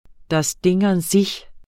Udtale [ das ˈdeŋ an ˈsiç ]